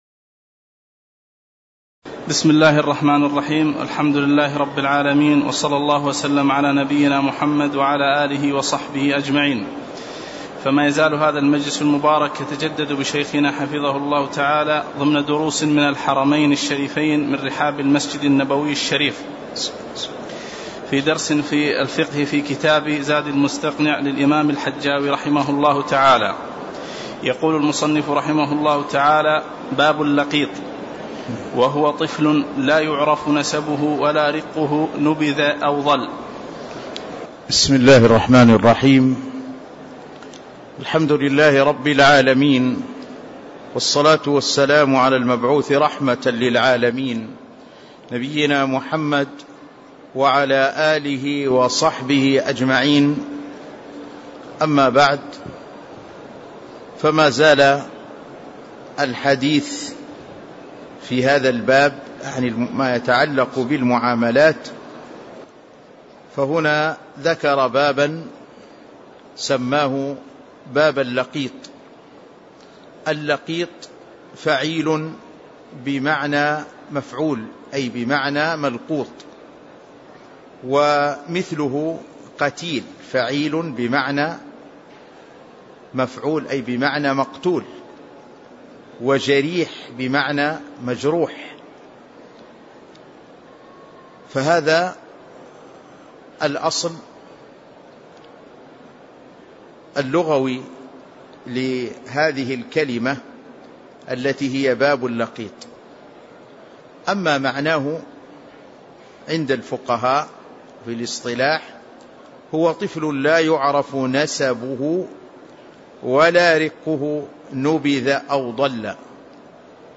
تاريخ النشر ٢ ربيع الأول ١٤٣٧ هـ المكان: المسجد النبوي الشيخ